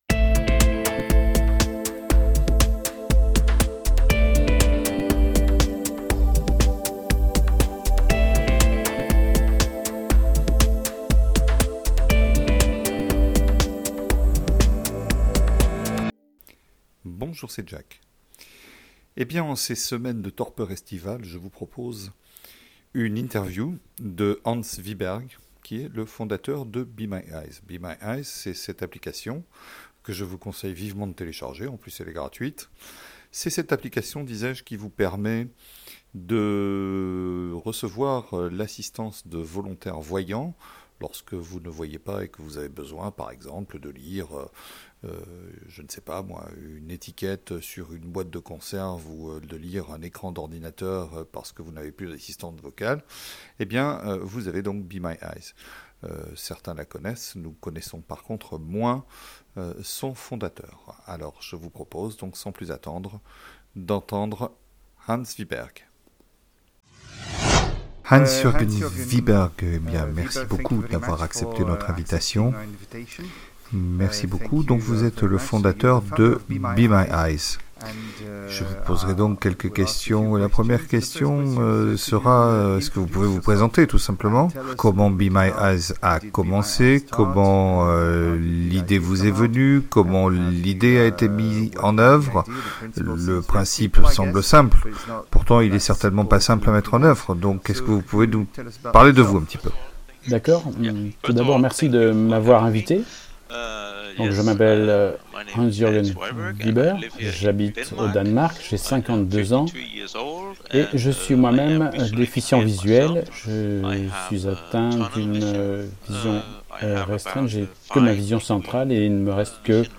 interview-BeMyEyes.mp3